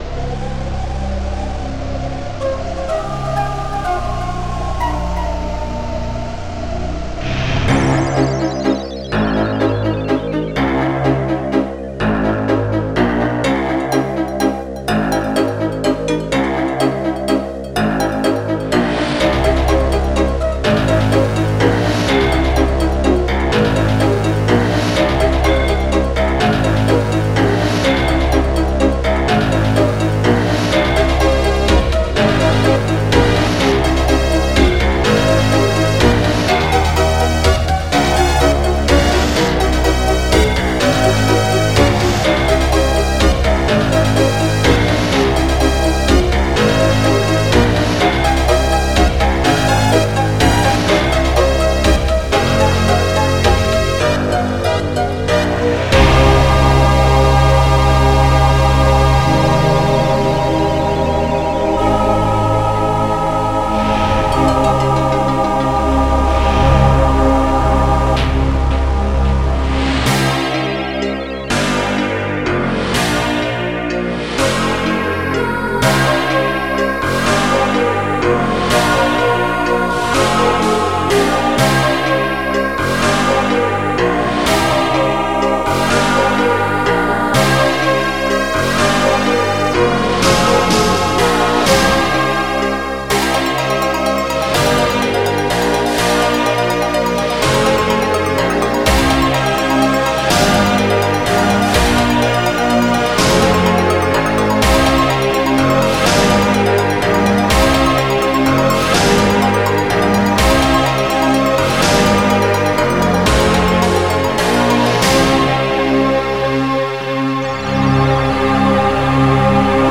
HugeBass
Short Violin
TechnoDistString
ReverseSnare
Strings
KettleDrum 1
Soft Voice
Backround twinkle
WindHowl.loop
An oversized, heavy tune